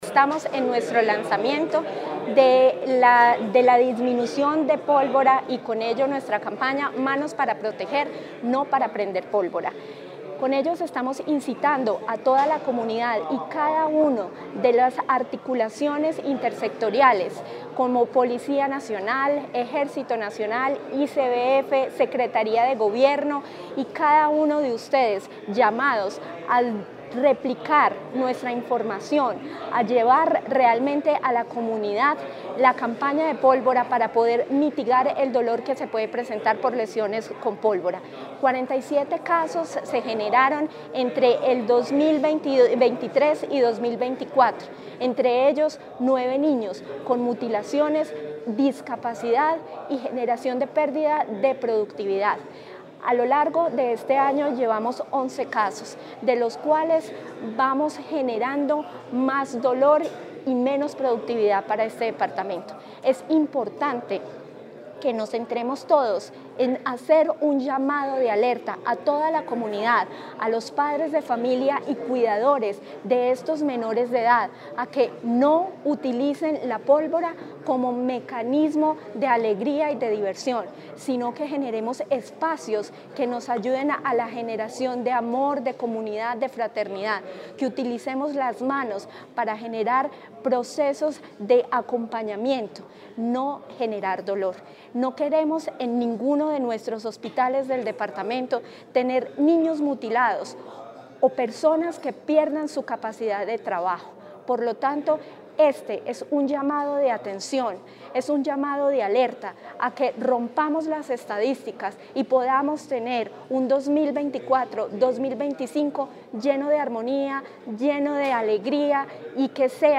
Natalia Castaño Díaz, directora de la DTSC.
AUDIO-NATALIA-CASTANO-DIAZ-DIRECTORA-DTSC-TEMA-LANZAMIENTO-CAMPANA-POLVORA.mp3